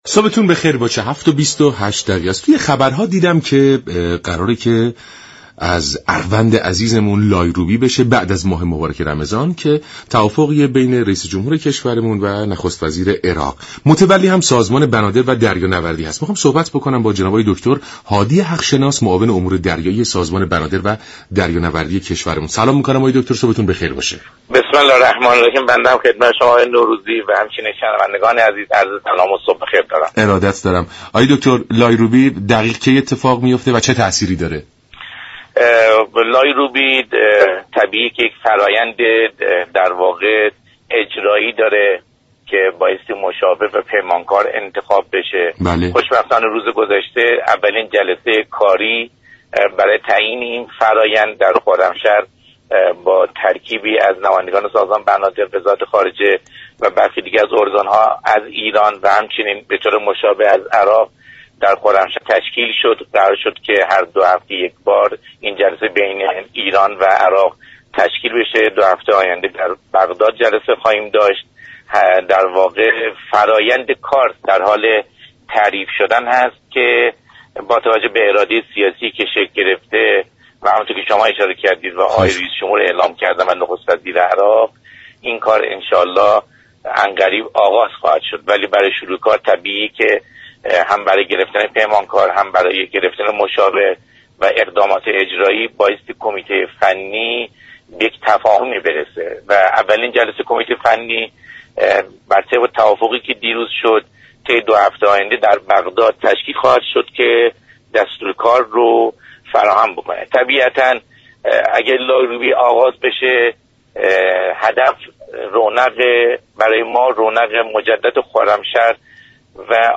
برنامه سلام صبح بخیر شنبه تا پنج شنبه هر هفته ساعت 6:35 از رادیو ایران پخش می شود. این گفت و گو را در ادامه می شنوید.